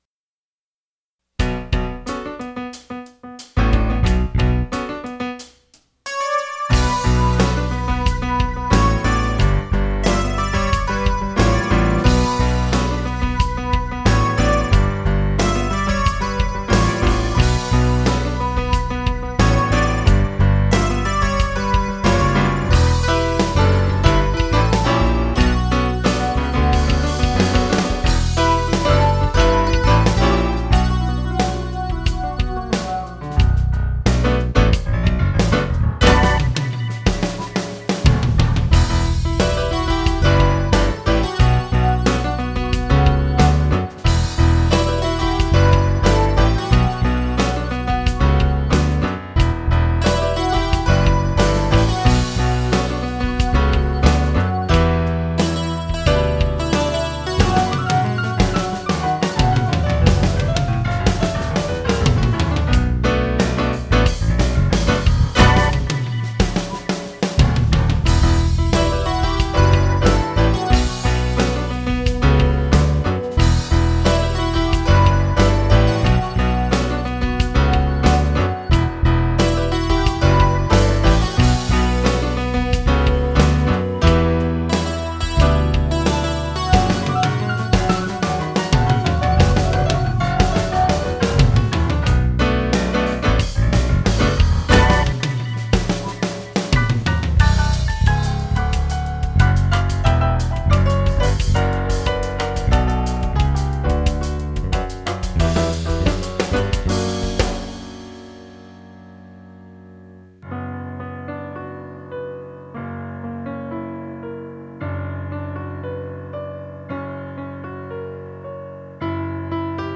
It had a lofty flow to it, like driving the convertible.